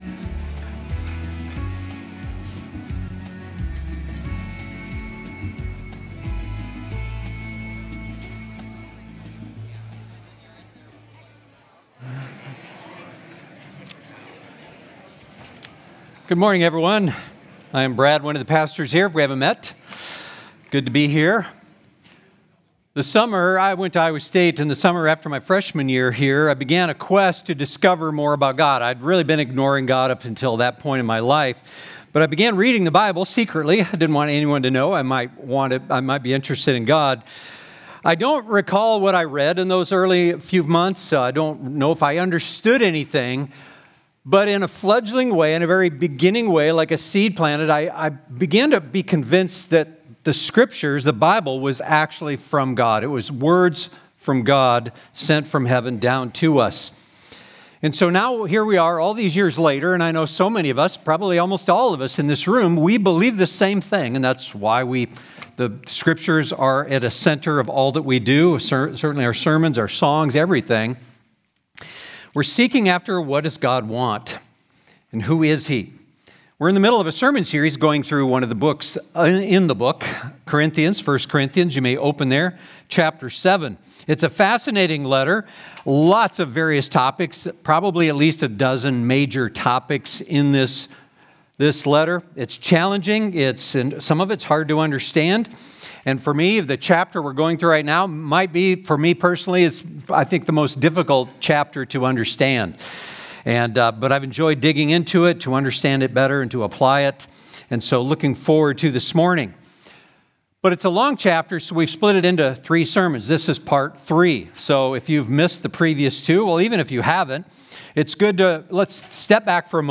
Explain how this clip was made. Stonebrook Sunday AM